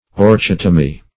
Orchotomy \Or*chot"o*my\, n. [Gr.